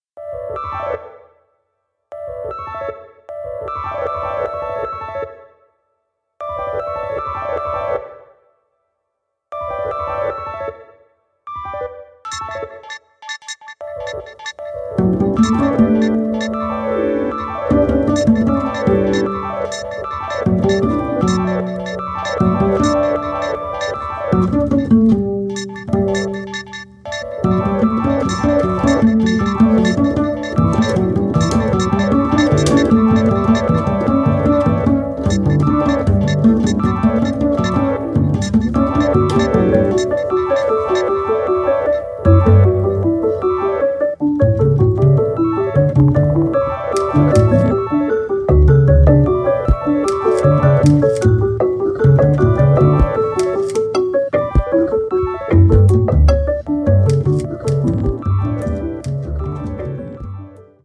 bass, voice